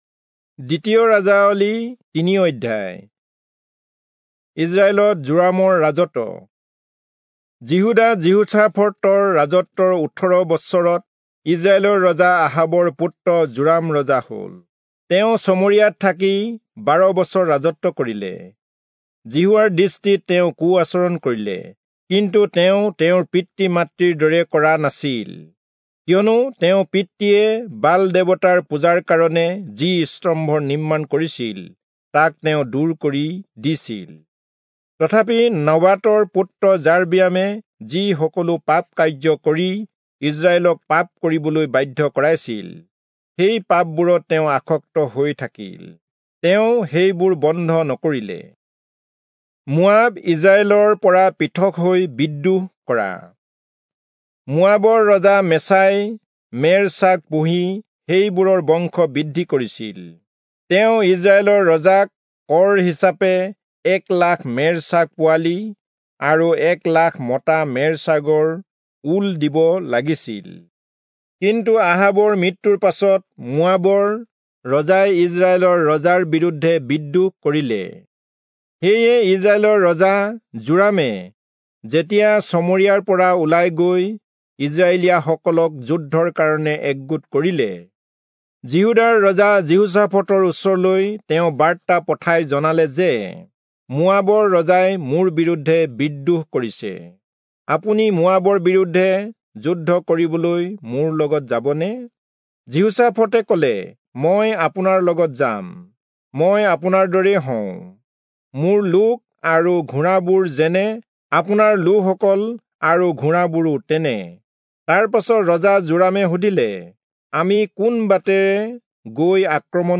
Assamese Audio Bible - 2-Kings 11 in Mhb bible version